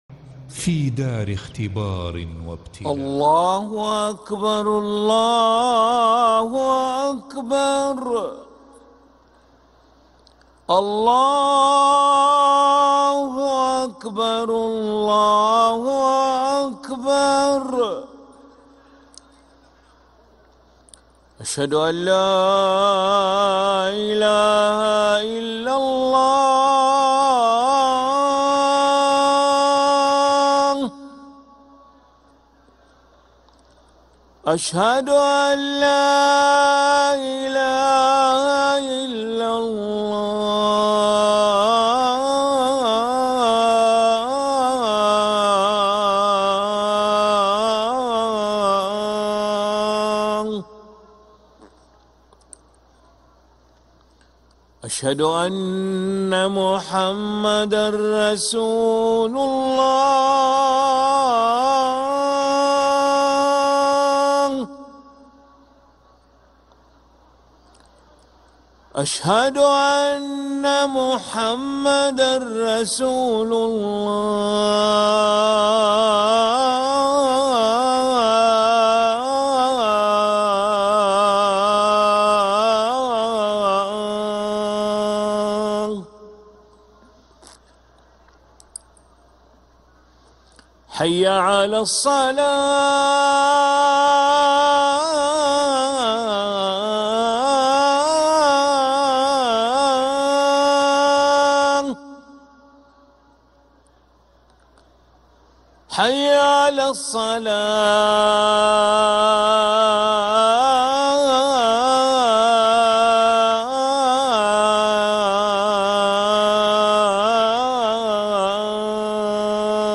أذان العشاء للمؤذن علي ملا الخميس 2 ربيع الأول 1446هـ > ١٤٤٦ 🕋 > ركن الأذان 🕋 > المزيد - تلاوات الحرمين